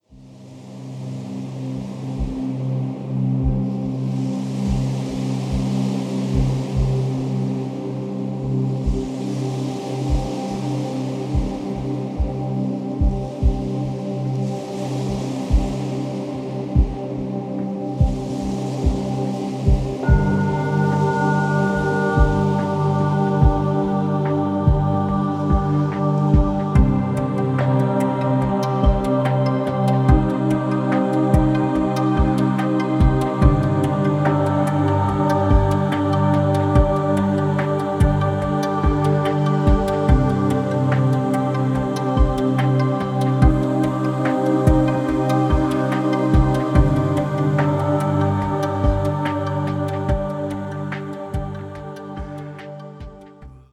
Eine besondere Art der spirituellen Entspannungsmusik …